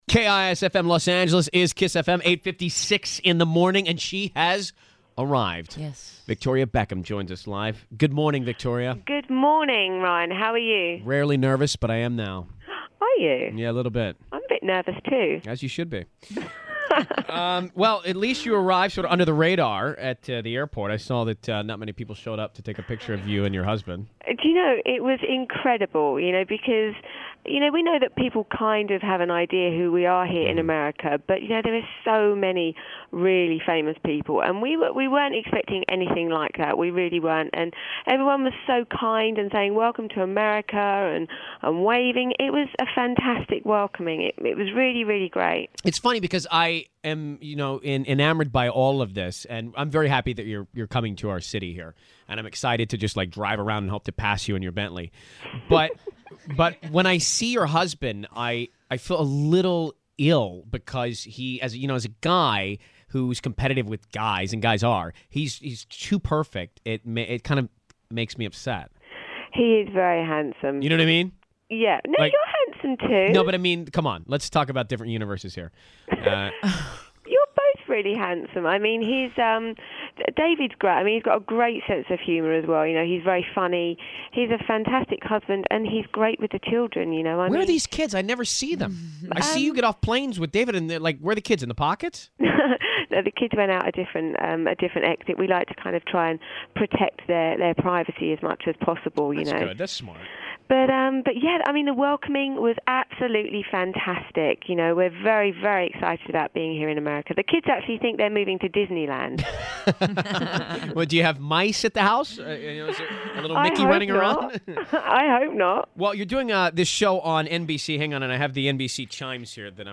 On Air with Ryan Seacrest - Victoria Beckham Interview (7.13.07).mp3